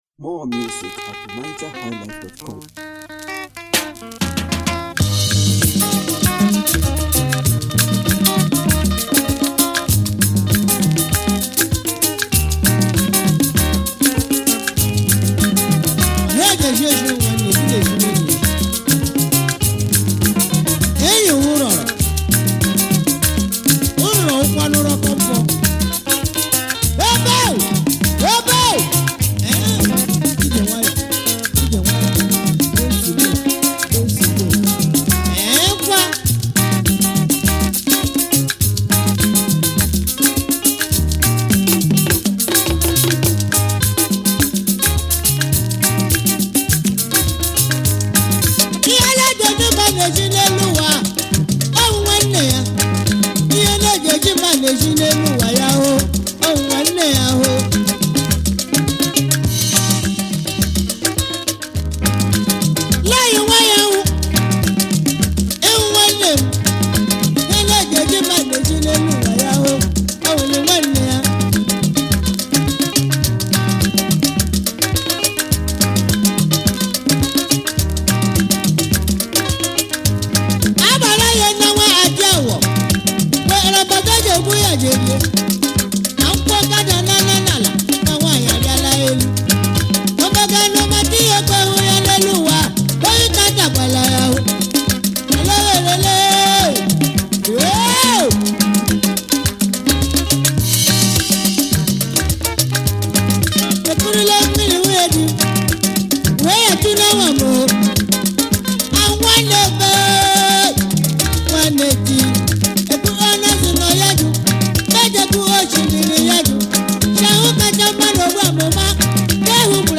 Nigeria most successful & popular highlife band